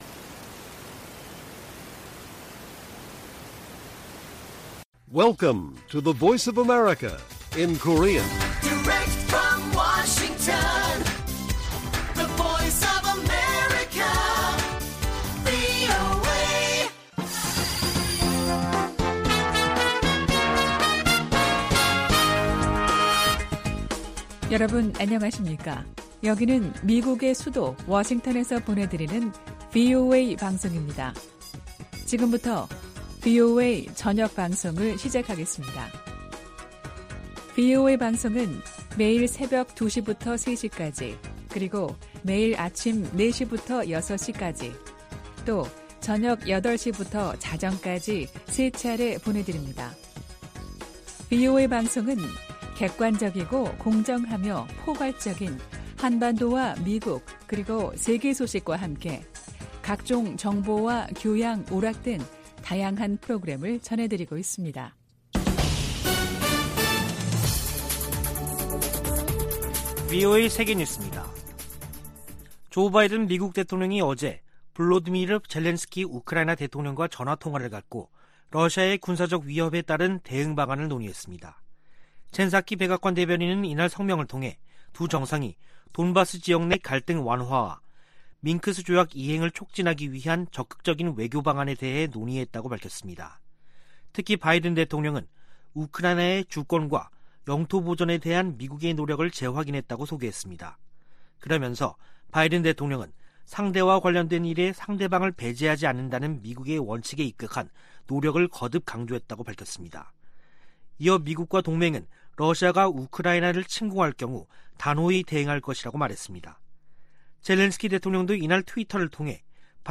VOA 한국어 간판 뉴스 프로그램 '뉴스 투데이', 2022년 1월 3일 1부 방송입니다. 문재인 한국 대통령이 한반도 평화 제도화 노력을 멈추지 않겠다고 신년사에서 밝혔습니다. 조 바이든 미국 대통령이 역대 최대 규모의 국방예산을 담은 2022국방수권법안에 서명했습니다. 탈북민들은 새해를 맞아 미국 등 국제사회가 북한 인권 문제에 더 초점을 맞출 것을 희망했습니다.